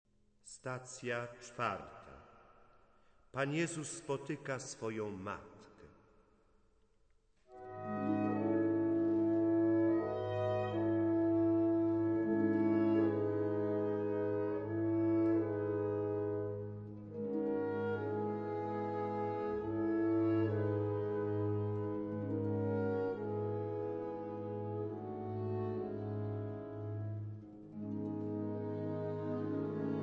sopran/soprano
baryton/baritone